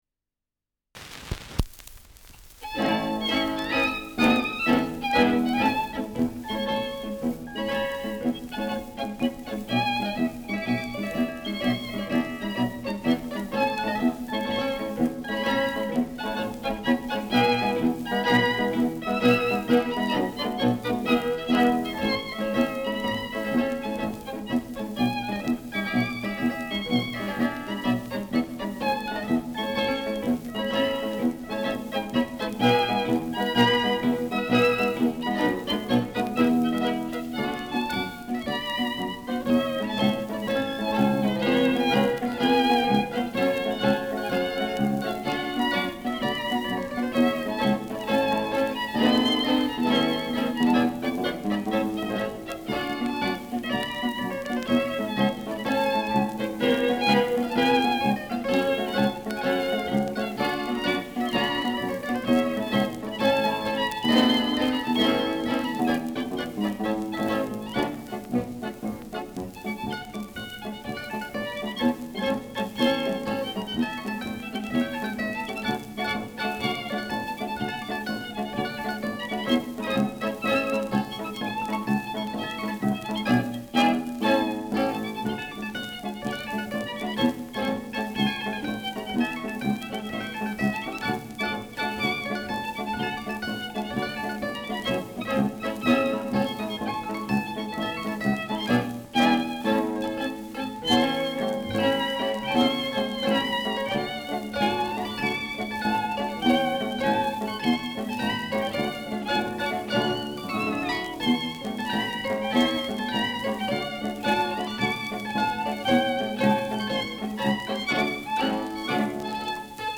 Schellackplatte
[München] (Aufnahmeort)